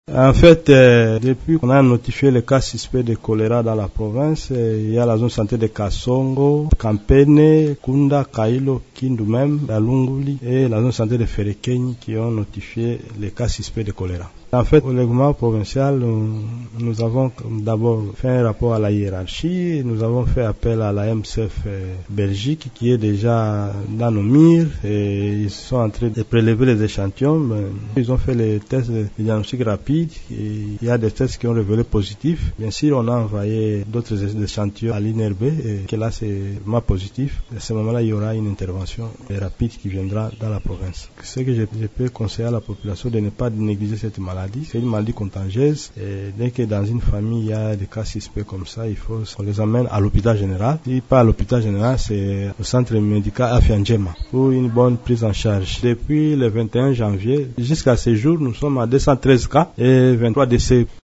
Écoutez le docteur Kingombe :